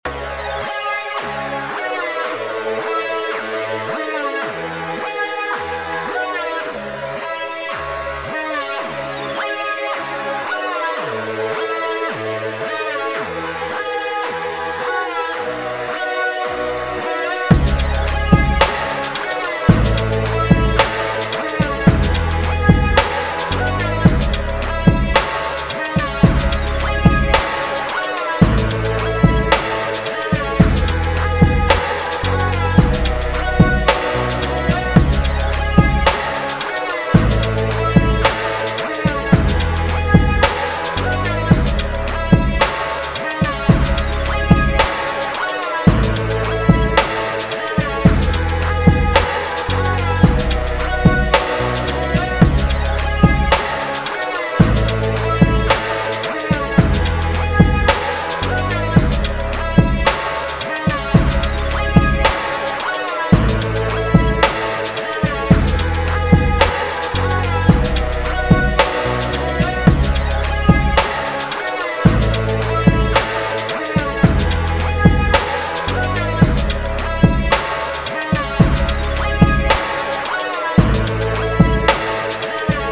HipHop
Instrumental